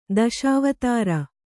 ♪ daśavatāra